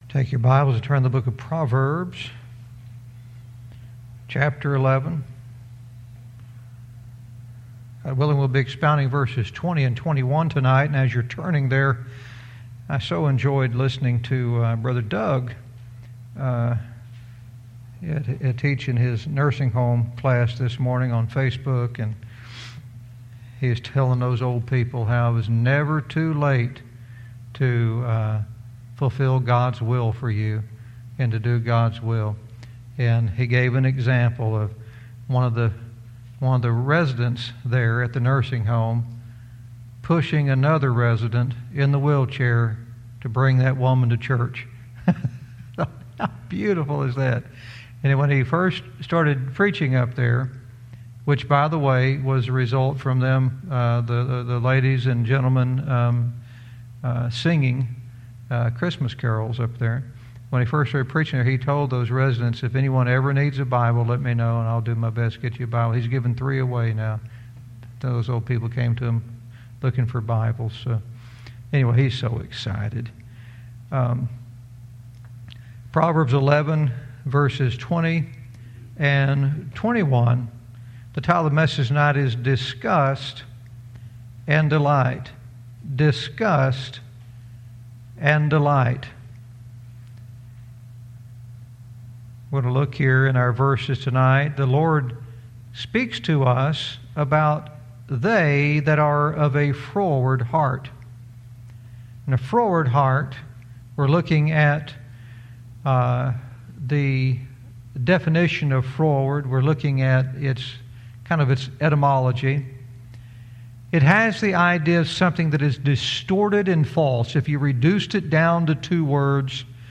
Verse by verse teaching - Proverbs 11:20-21 "Disgust and Delight"